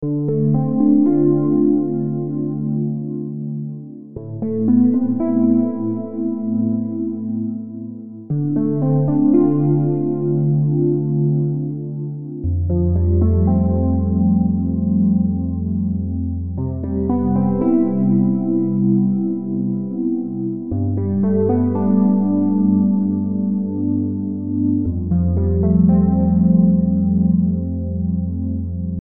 Relaxing Dreamy Synth Rhodes Background Music Loop 116 BPM
Genres: Synth Loops
Tempo: 116 bpm